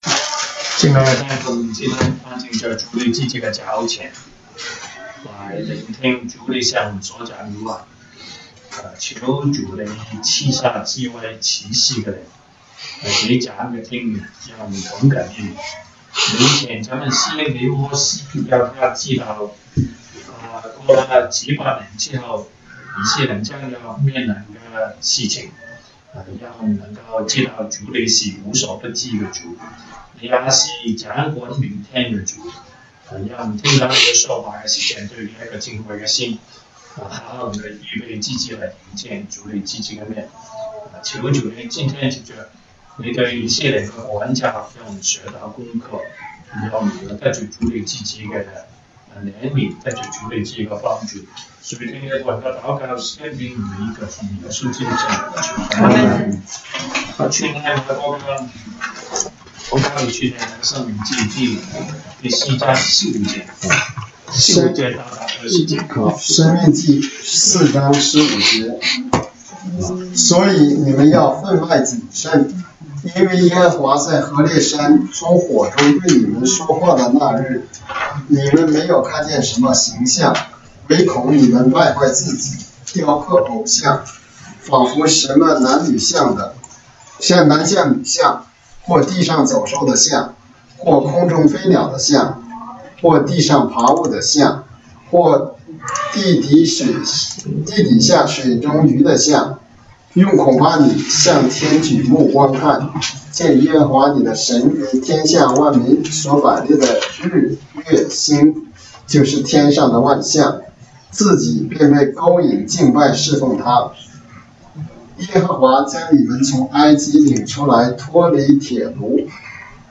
週五國語研經 Friday Bible Study « 週一國語研經